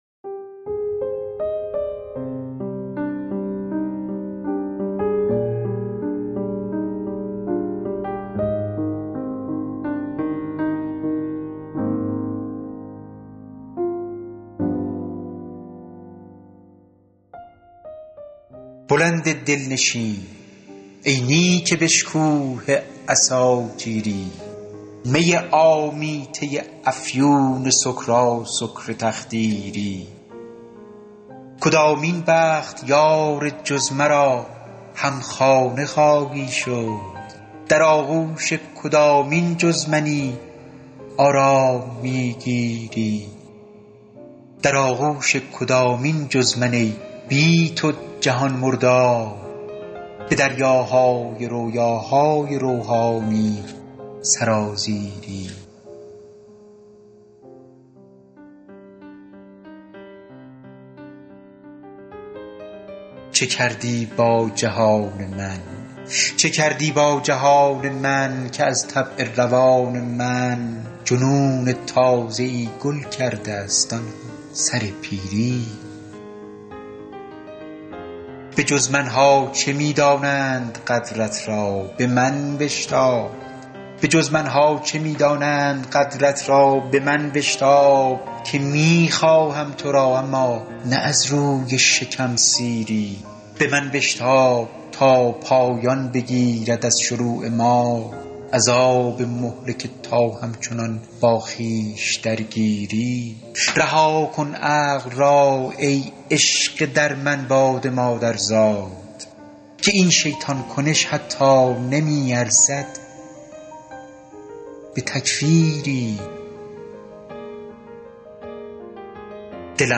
دانلود دکلمه برتر چالش